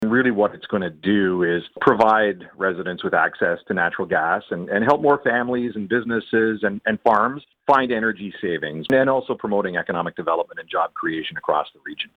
Bay of Quinte MPP Todd Smith.
todd-smith-1.mp3